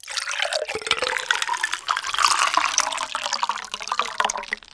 waterfind.ogg